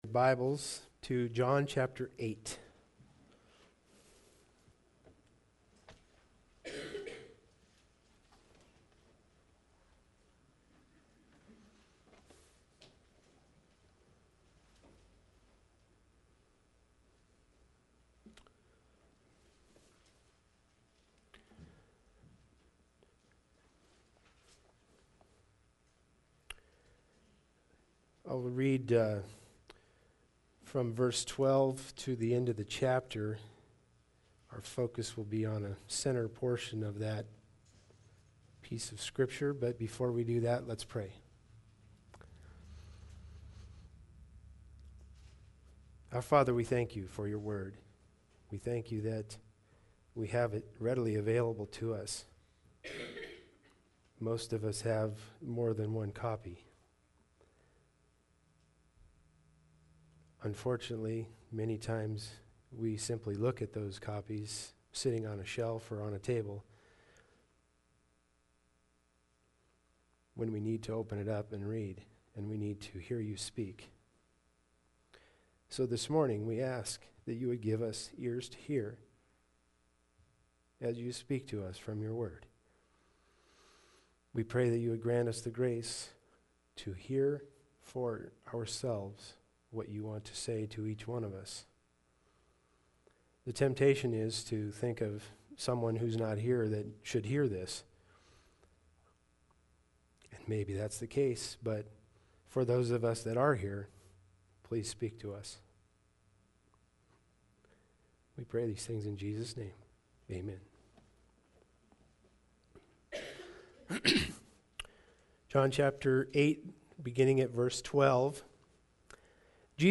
John 8:12-59 Service Type: Sunday Service Bible Text